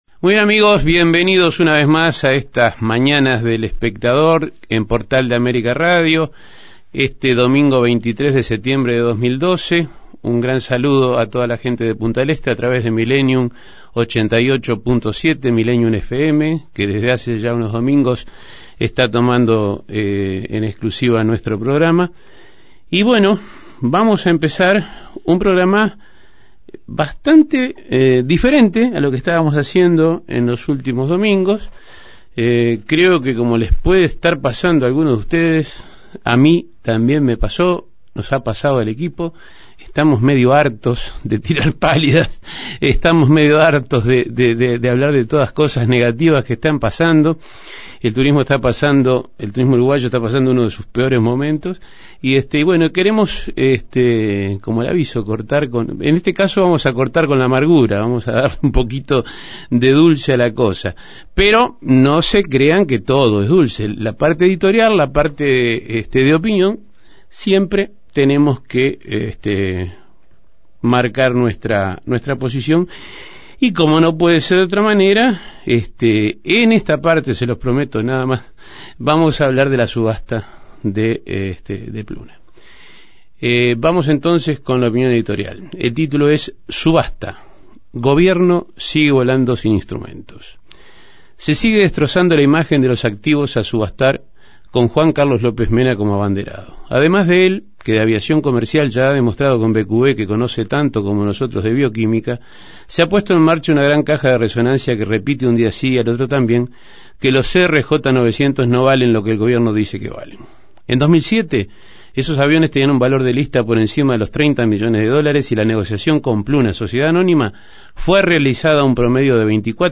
acompañándonos a "Pensar el turismo", tema para el que lo invitamos a dialogar. El Museo del Fútbol al que se refiere, es el que está en el Estadio Centenario de Montevideo.